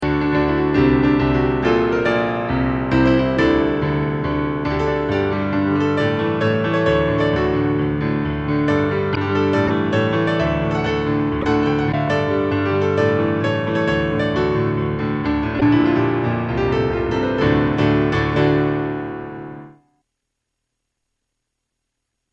Piano
勝利ファンファーレ1
P.Sまぁ音が悪いのは、レコードのせいだと好意的に解釈してください。